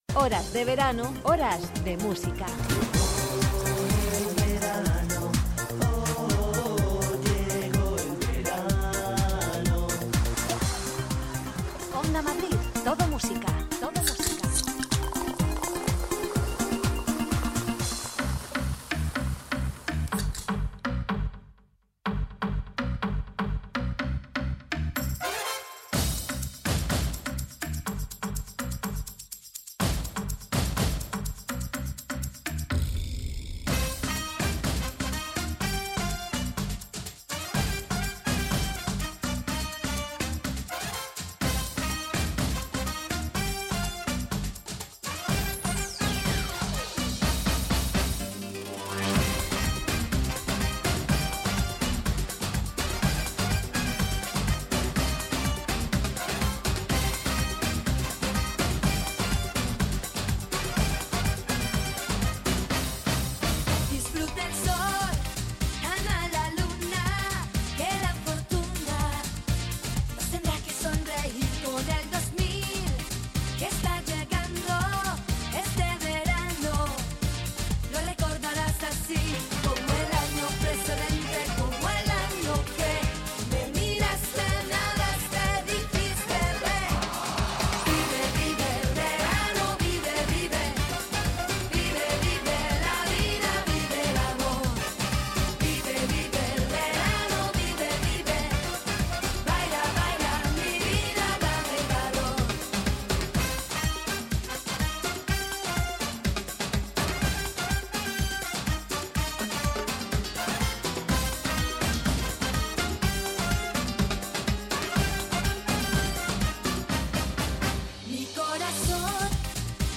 Un año más desde la radio abrimos La Terraza de Onda Madrid Todo Música para acompañarte con los éxitos que bailaste en todos tus veranos.